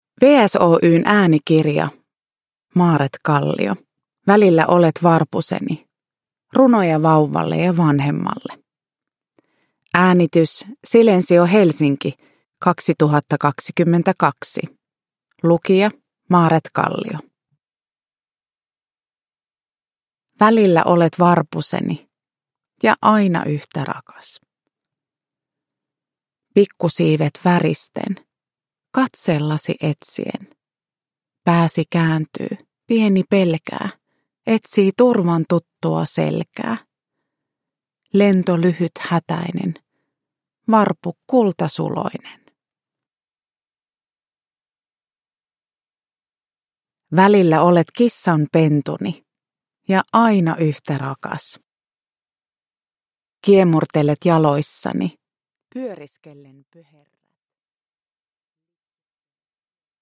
Välillä olet varpuseni – Ljudbok – Laddas ner